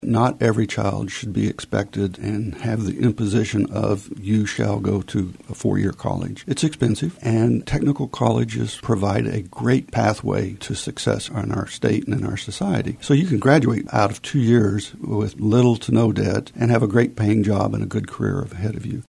Tom Phillips, a Kansas House of Representative member representing the 67th district, appeared on today’s episode of In Focus to preview some of what he will be working on in the upcoming legislative session in the areas of healthcare, higher education and “dark store theory.”